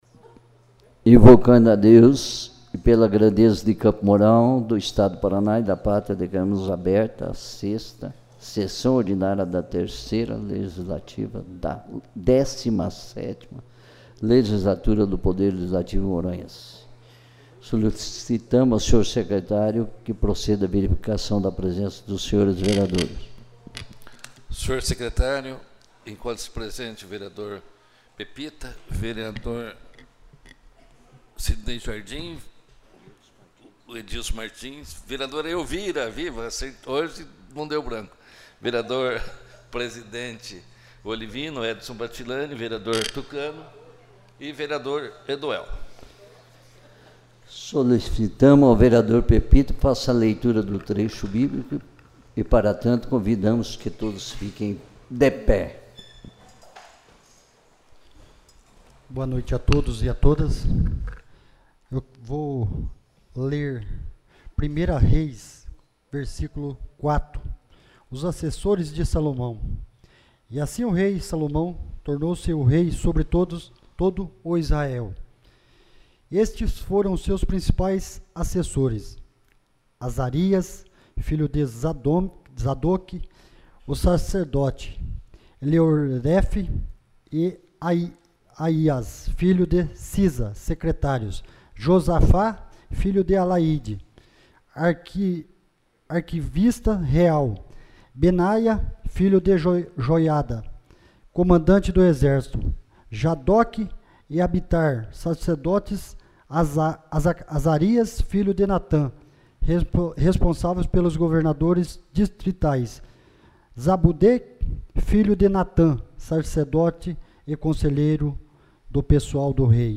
6ª Sessão Ordinária